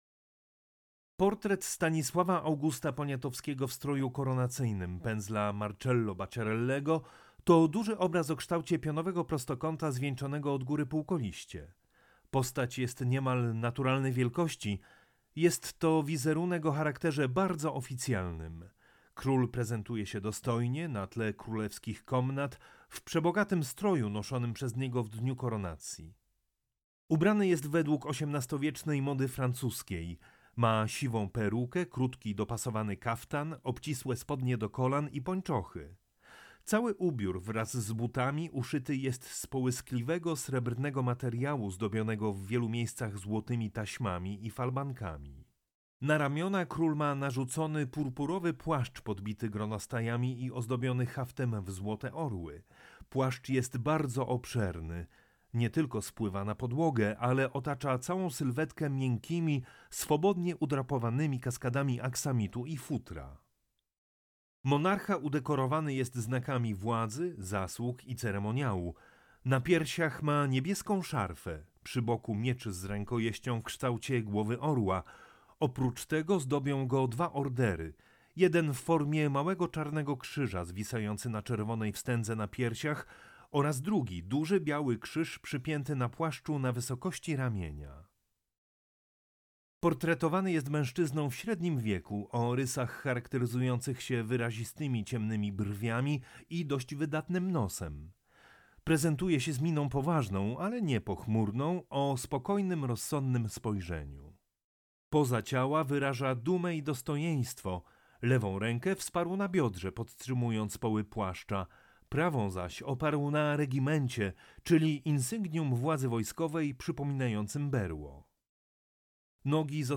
AUDIODESKRYPCJA
AUDIODESKRYPCJA-Marcello-Bacciarelli-Portret-Stanislawa-Augusta-Poniatowskiego.mp3